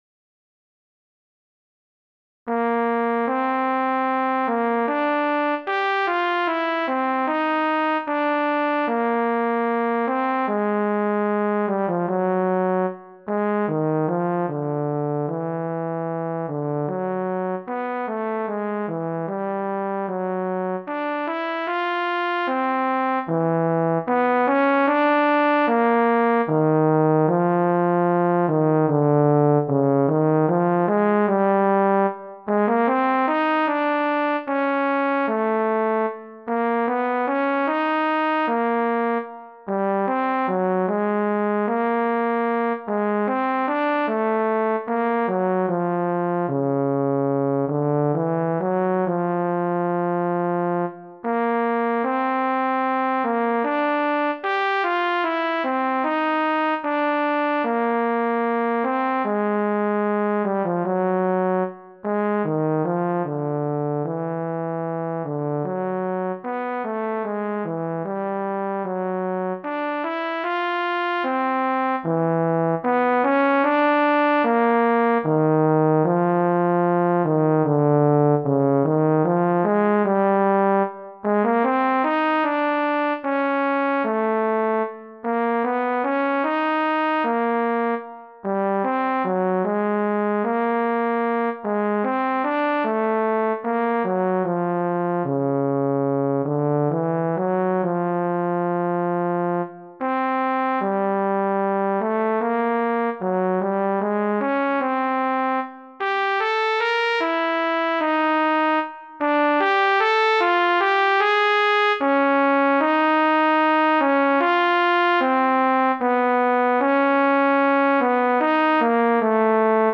(Sarabande)